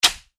FlipCard_Hit.mp3